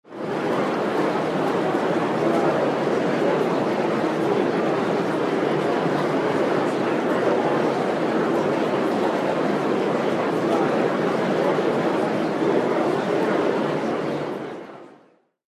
concert-crowd.mp3